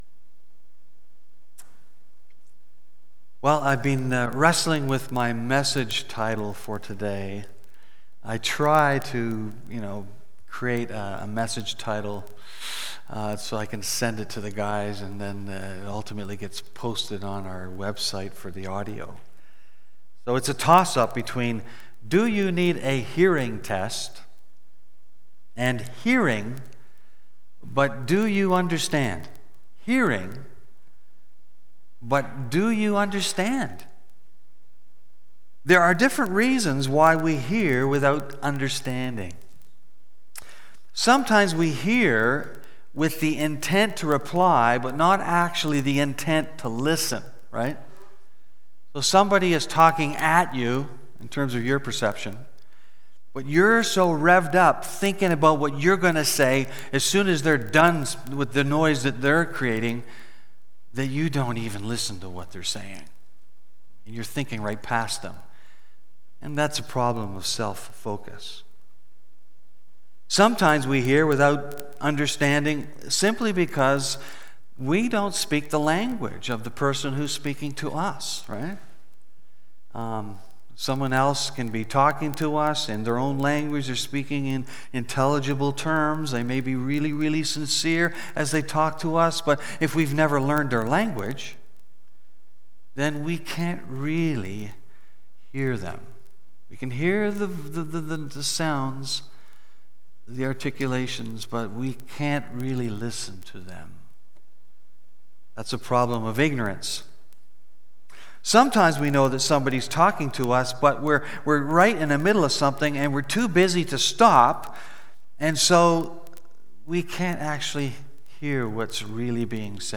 Part 74 BACK TO SERMON LIST Preacher